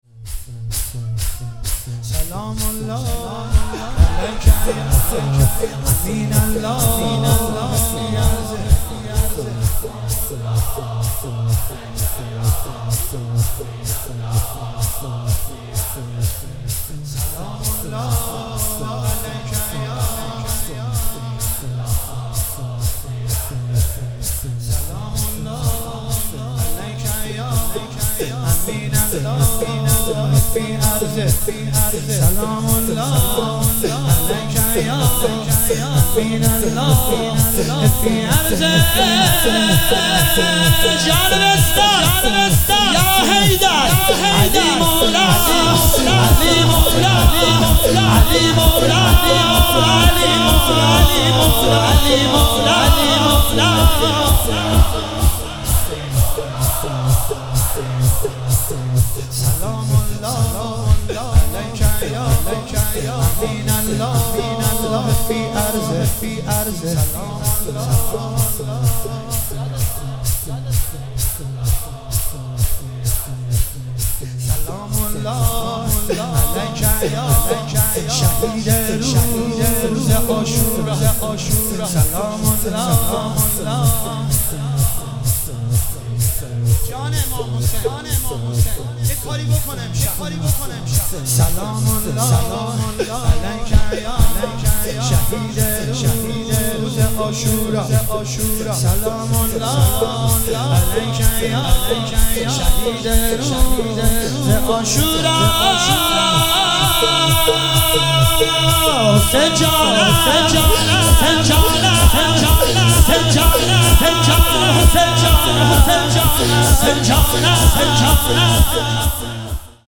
هیئت زوار البقیع طهران